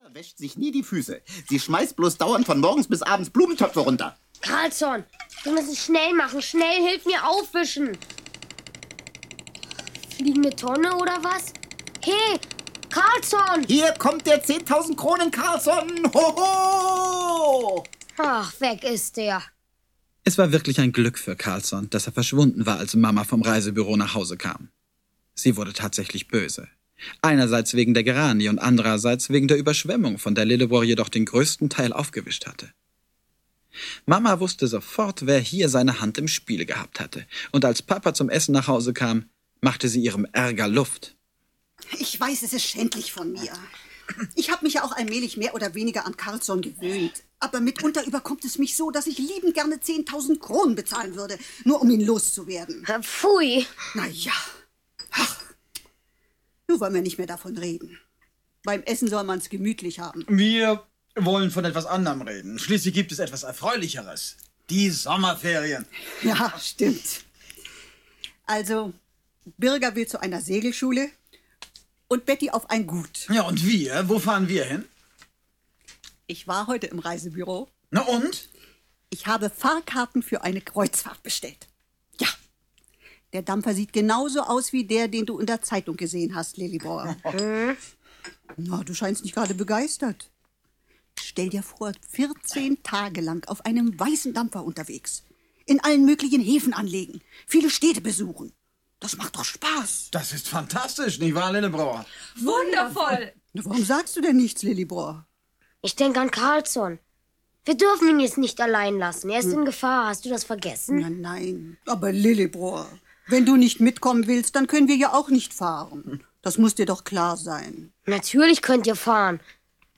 Karlsson vom Dach 3. Der beste Karlsson der Welt. Hörspielklassiker - Astrid Lindgren - Hörbuch